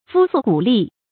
膚粟股栗 注音： ㄈㄨ ㄙㄨˋ ㄍㄨˇ ㄌㄧˋ 讀音讀法： 意思解釋： 皮膚上起疙瘩，大腿發抖。形容非常恐懼。